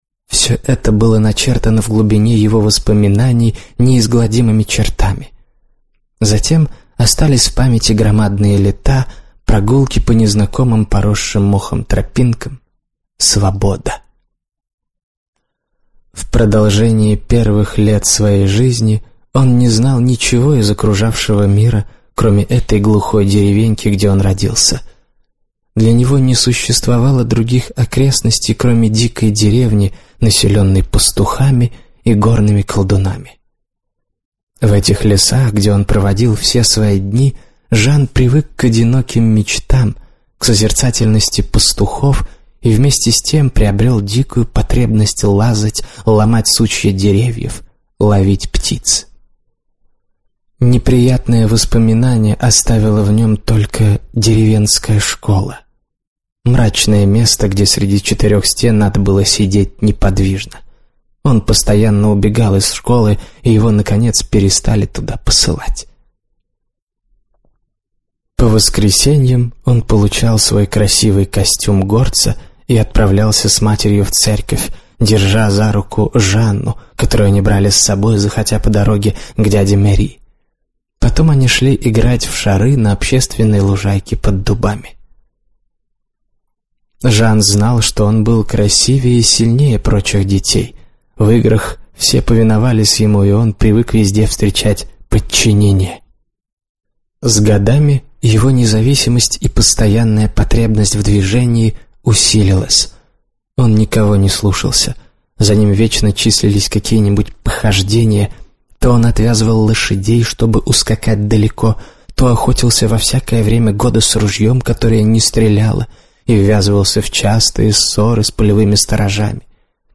Аудиокнига Рабыня | Библиотека аудиокниг
Прослушать и бесплатно скачать фрагмент аудиокниги